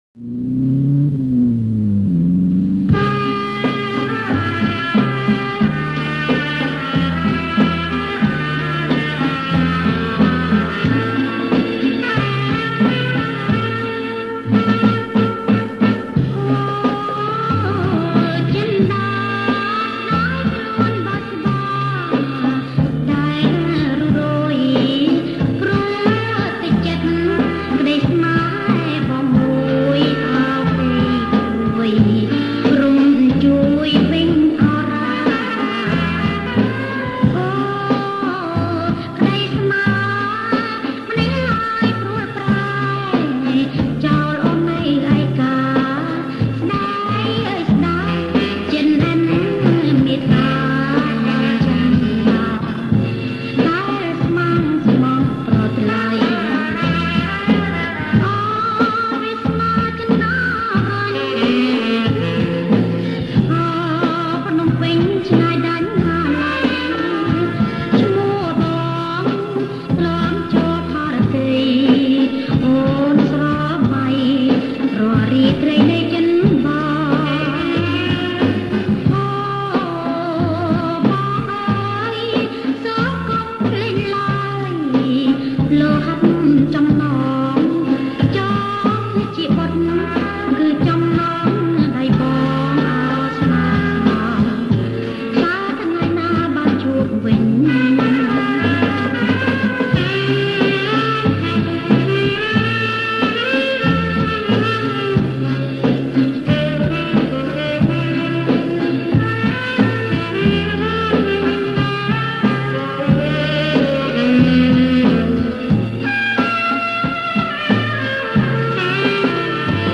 • ប្រគុំជាចង្វាក់ Bolero leut